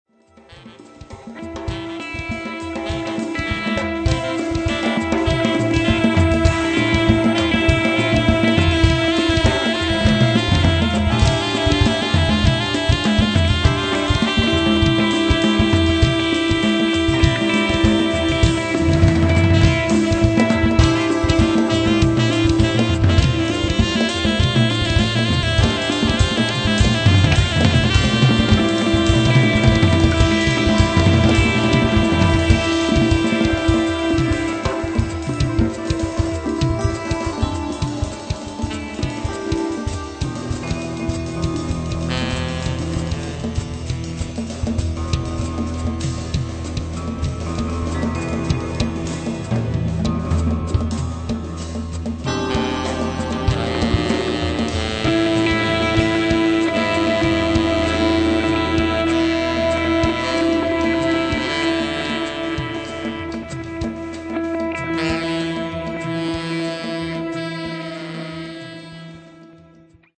recorded at Midtown Recording